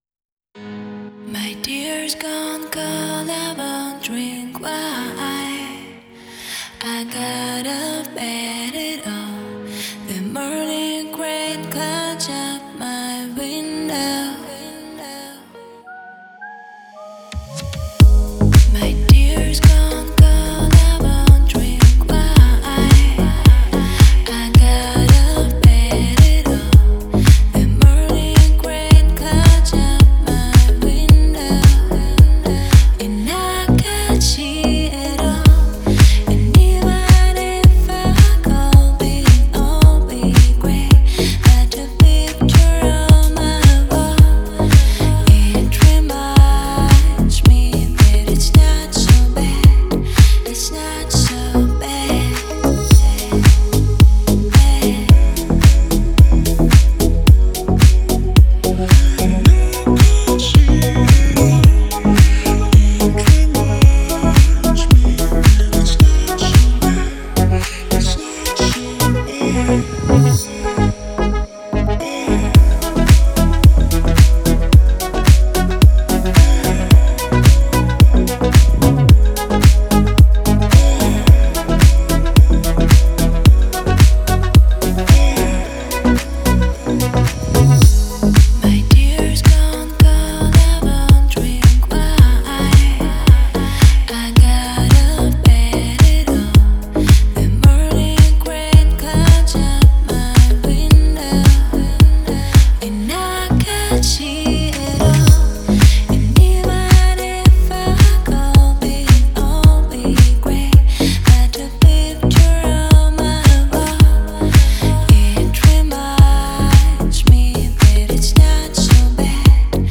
Deep House музыка
красивый Deep House